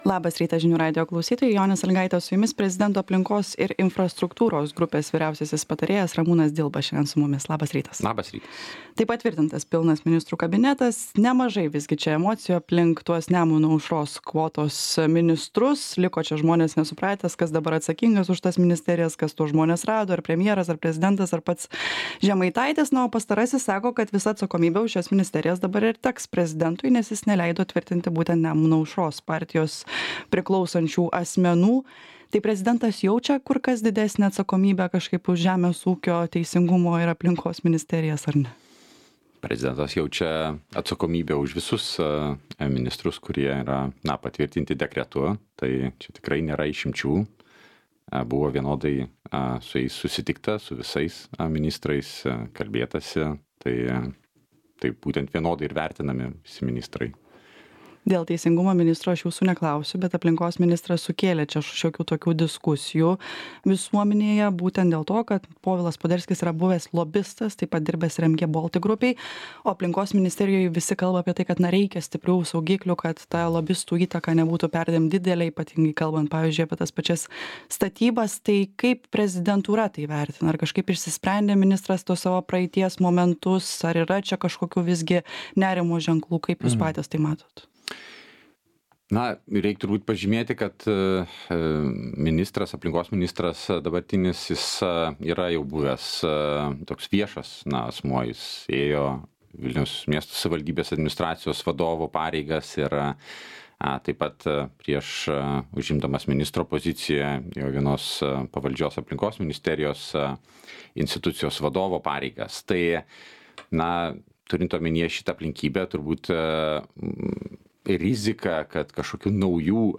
Pokalbis su prezidento vyriausiuoju patarėju Ramūnu Dilba.